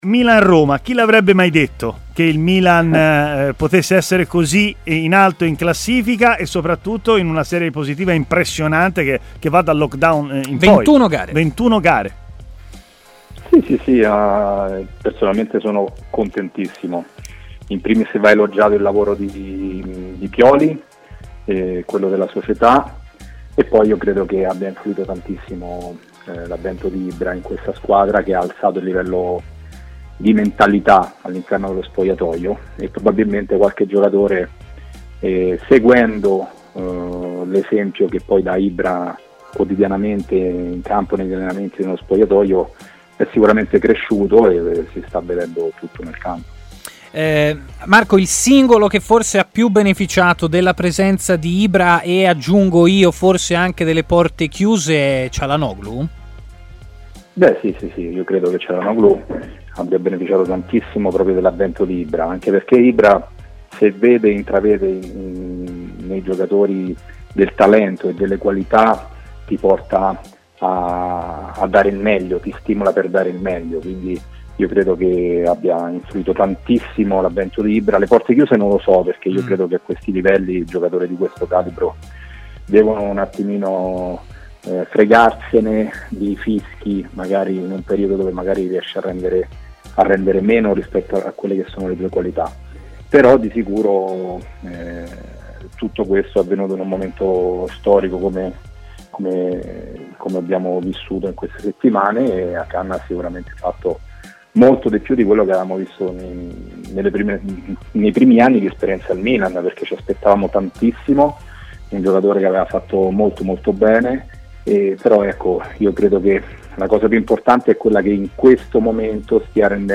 L'ex portiere Marco Amelia si è collegato in diretta ai microfoni di TMW Radio, intervenendo nel corso della trasmissione Stadio Aperto.